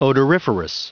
Prononciation du mot odoriferous en anglais (fichier audio)